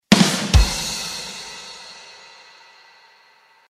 Звуки бадум тсс